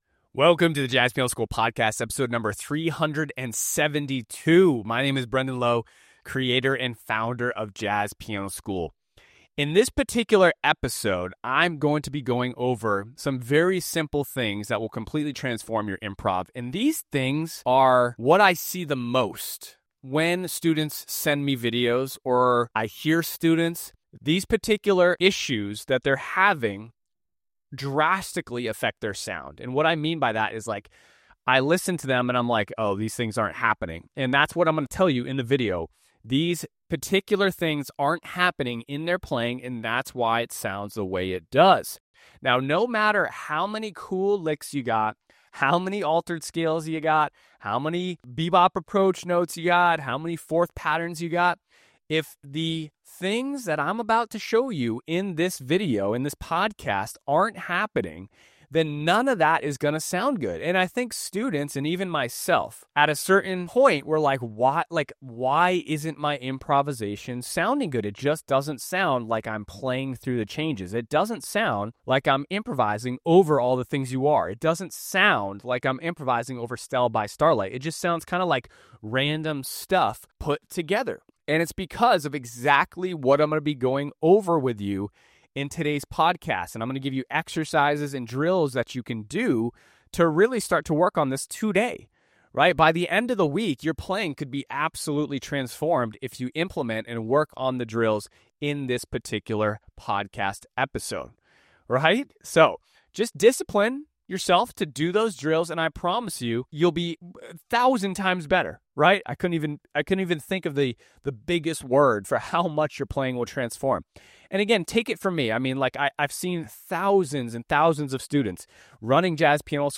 podcast lesson